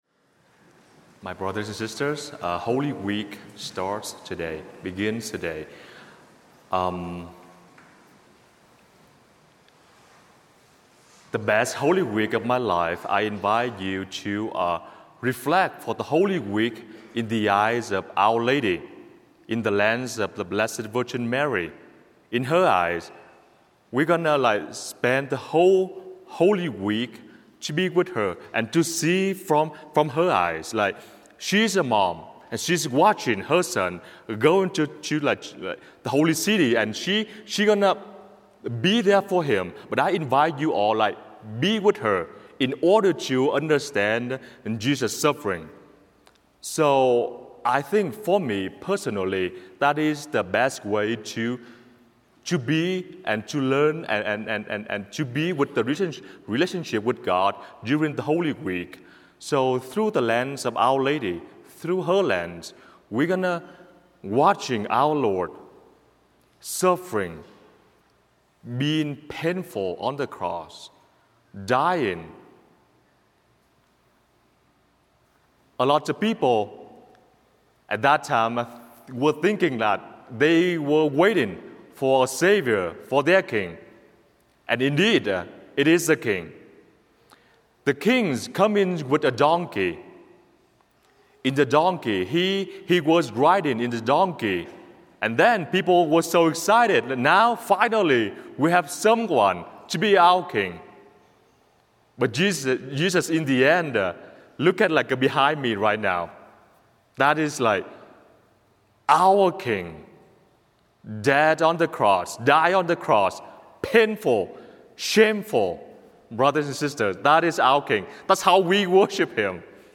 Palm Sunday Homily
palm-sunday-homily.mp3